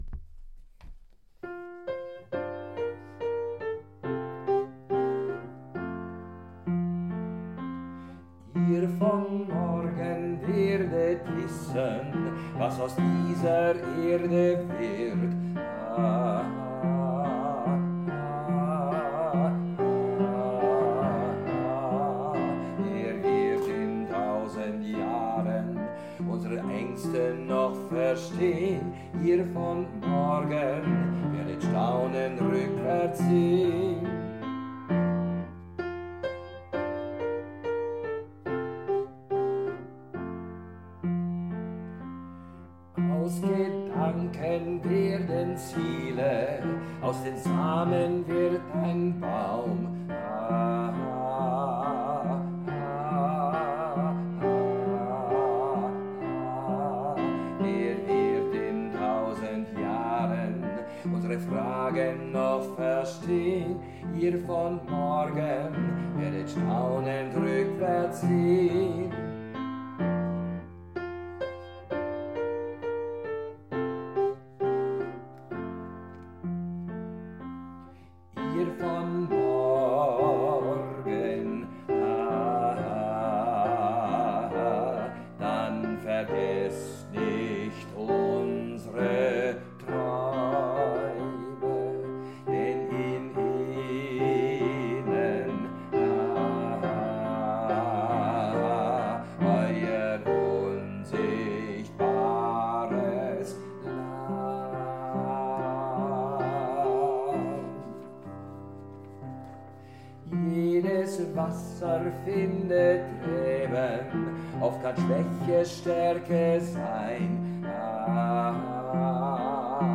Und das erreichen wir mit den Probefiles, also Übungsmaterial zum Anhören und mitsingen unserer Stücke.
IhrVonMorgen Tenor1 IhrVonMorgen Tenor2 IhrVonMorgen Bass1 IhrVonMorgen Bass2 IhrVonMorgen vierstimmig Arragement Verlag Korbach Ein Beispiel dafür Alle Sänger erhalten den Zugang zur Dropbox wo alle Stücke – für jede Singstimme extra– eingesungen sind.
IhrVonMorgen-Bass2.mp3